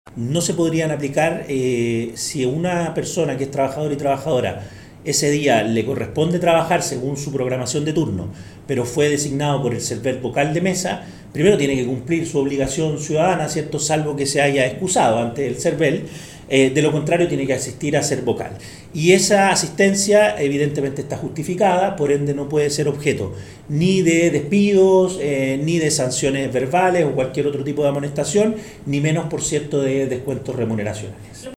Ello fue explicado por el director del Trabajo, Pablo Zenteno.